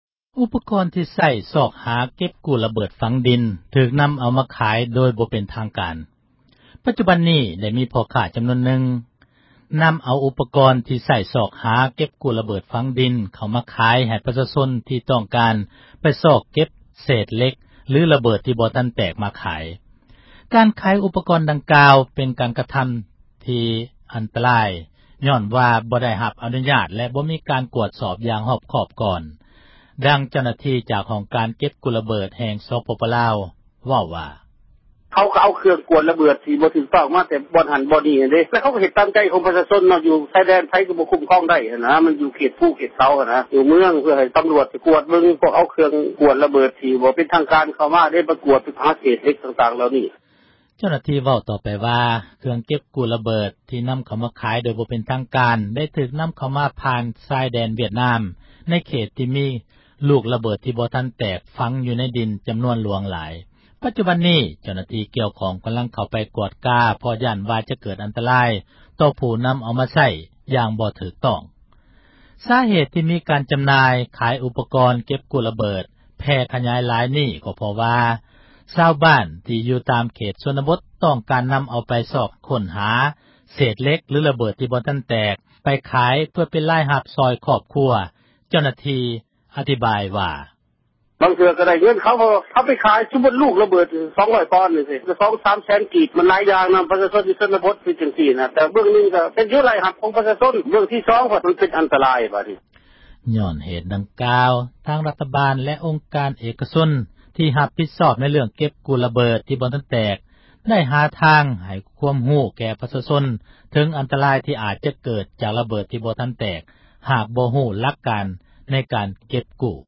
ຊາວບ້ານໃຊ້ເຄື່ອງມື ຈັບສັນຍານ ເກັບລະເບີດ – ຂ່າວລາວ ວິທຍຸເອເຊັຽເສຣີ ພາສາລາວ
ດັ່ງເຈົ້າໜ້າທີ່ ຈາກອົງການ ເກັບກູ້ລະເບີດ ແຫ່ງສປປລາວ ເວົ້າວ່າ: